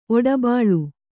♪ oḍabāḷu